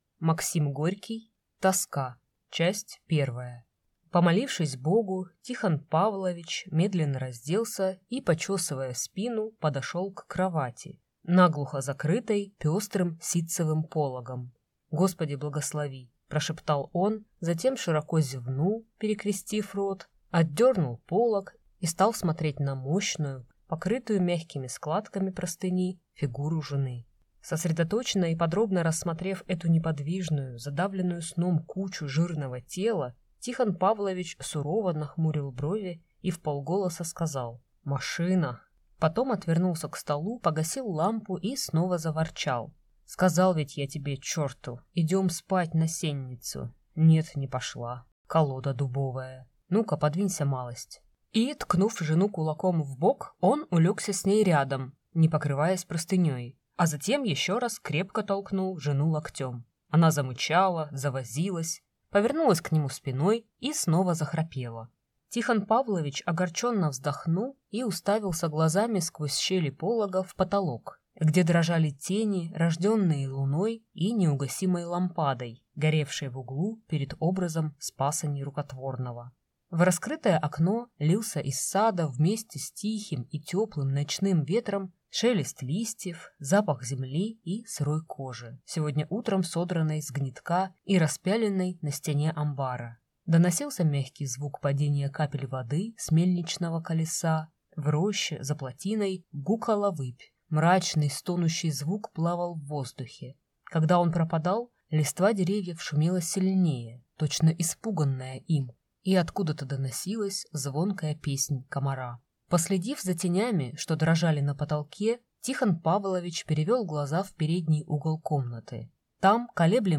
Аудиокнига Тоска | Библиотека аудиокниг